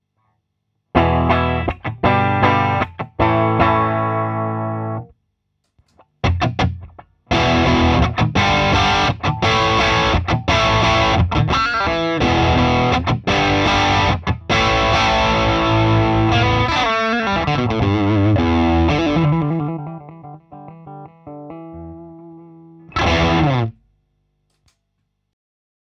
“Full gain” bridge humbucker